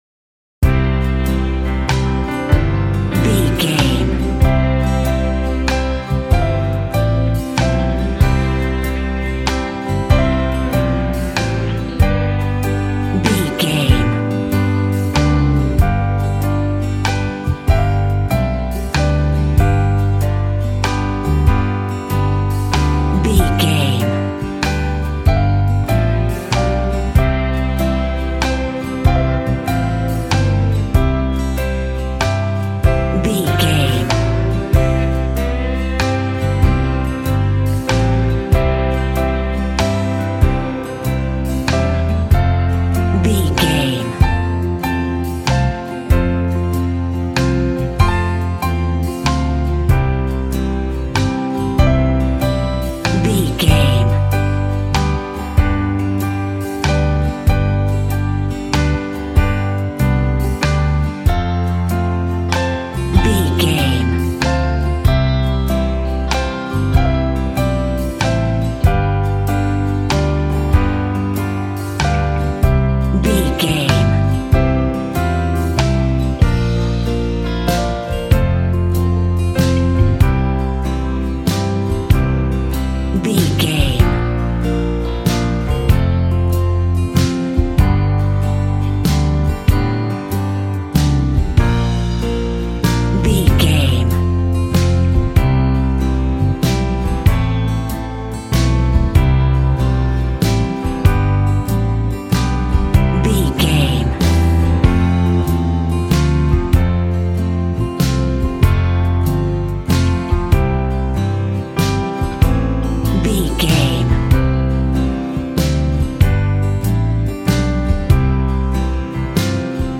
Ionian/Major
cheerful/happy
double bass
drums
piano
50s